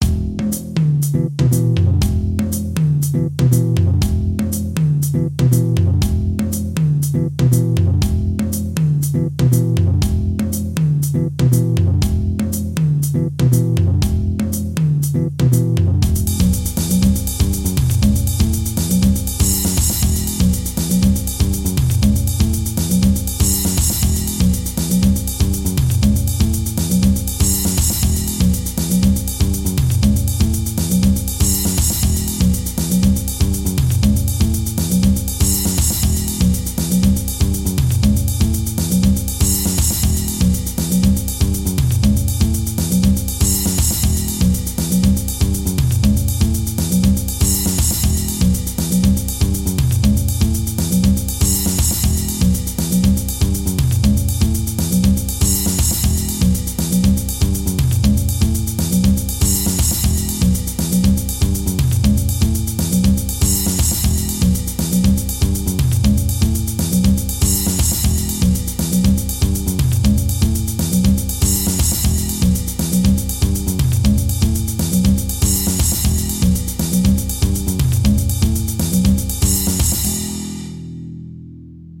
Legato Exercise over a Brazilian baiao backing track.
Backing Track
It’s a “baiao” from Brazil.